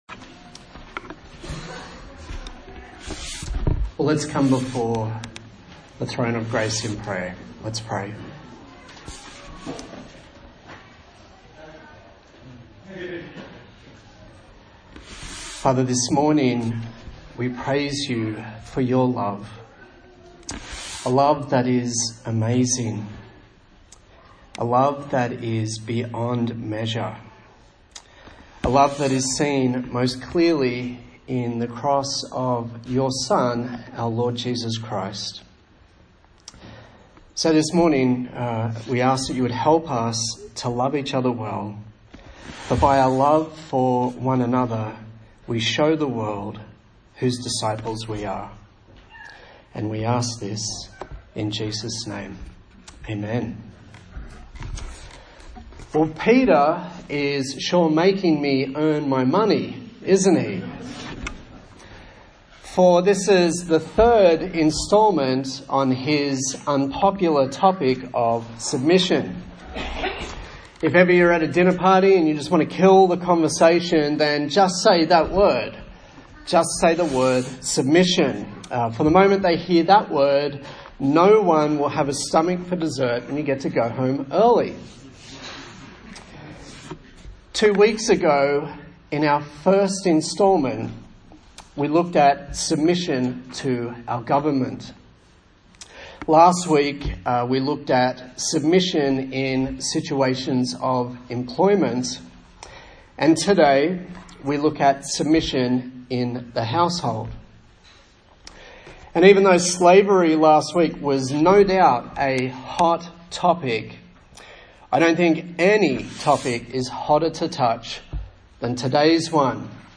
A sermon in the series on the book of 1 Peter
Service Type: Sunday Morning